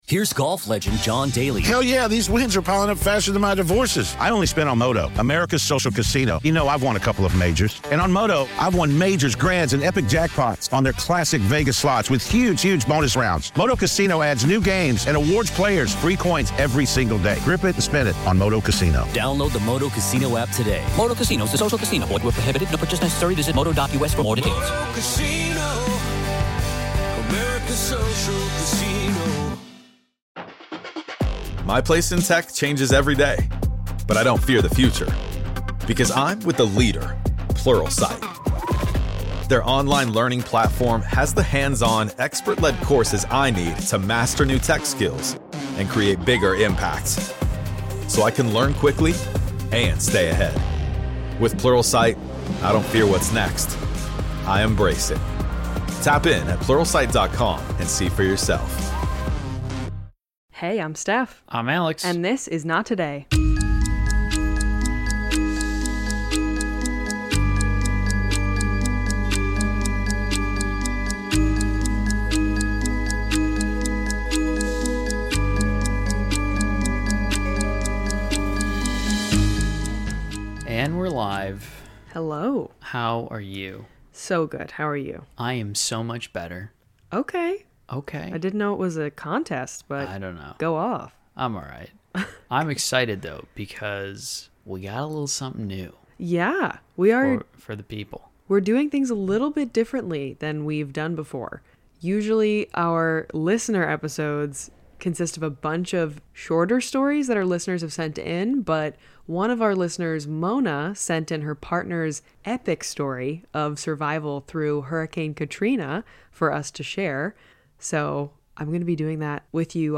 Join us this week as we read one of our listeners first hand accounts of their journey through Hurricane Katrina.